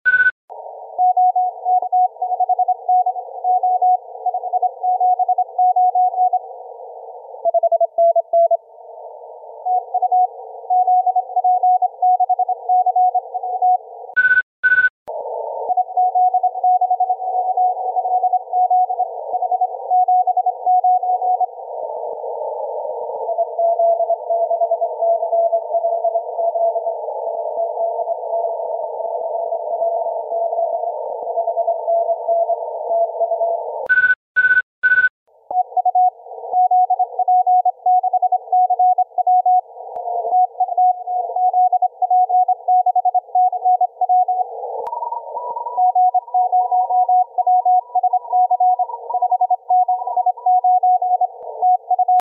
I have marked the start of the inverted L with one beep, the dipole with two beeps, and the Steppir with three.
Here, the inverted L is noticeably stronger than the trapped dipole.